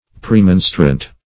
Premonstrant \Pre*mon"strant\, n. A Premonstratensian.